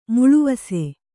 ♪ muḷuvase